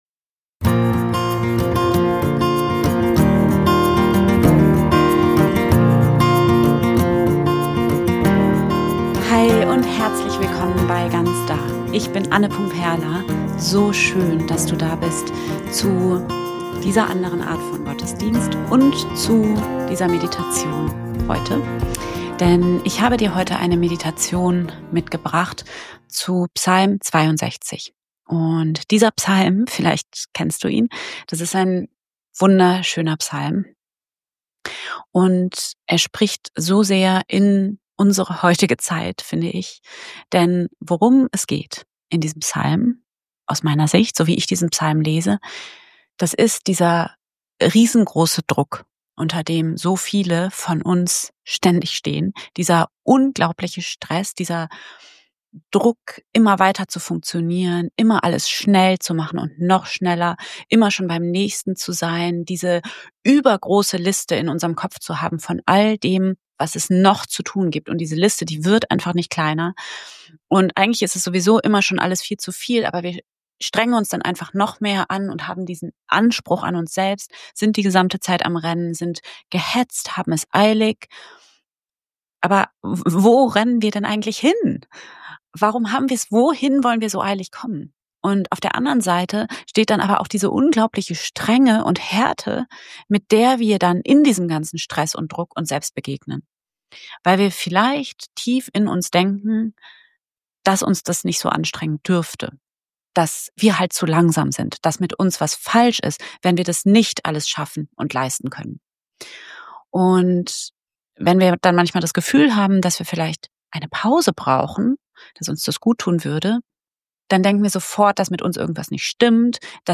In dieser geführten Meditation leite ich dich durch eine sanfte Begegnung mit Psalm 62 – persönlich, vertrauensvoll, berührend.